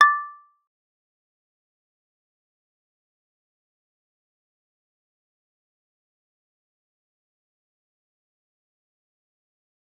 G_Kalimba-D6-f.wav